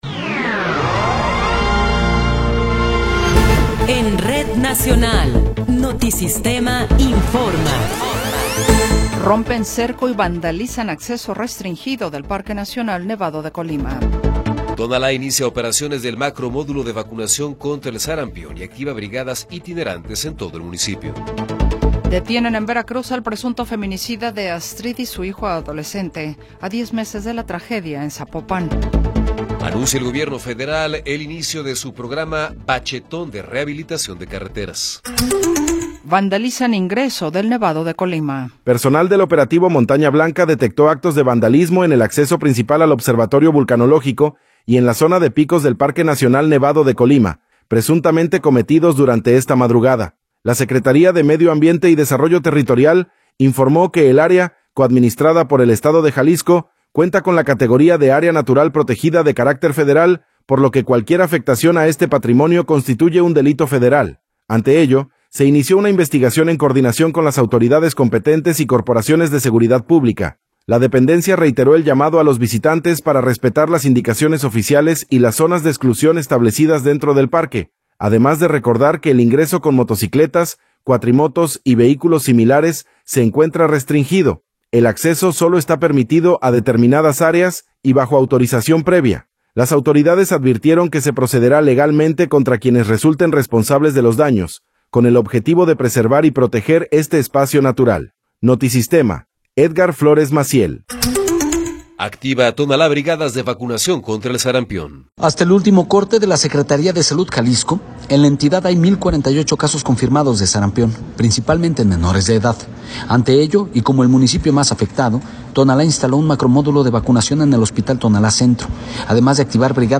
Noticiero 14 hrs. – 21 de Enero de 2026